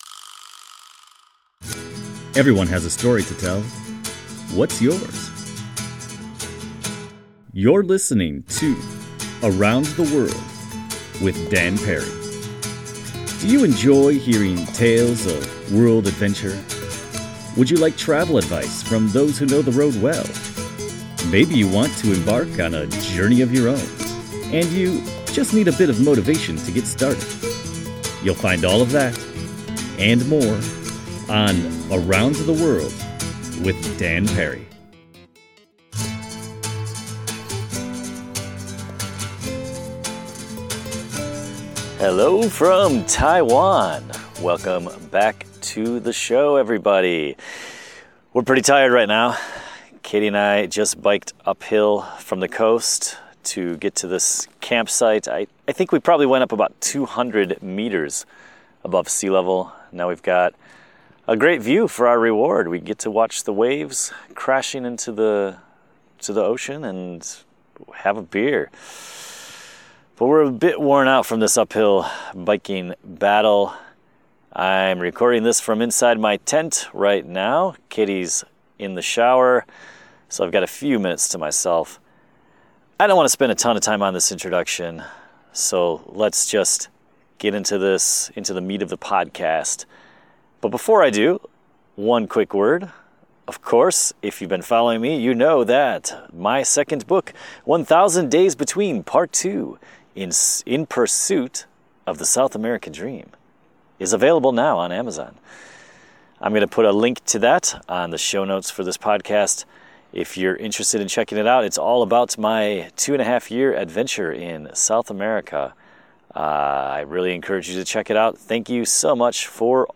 This podcast contains a snippet of those conversations.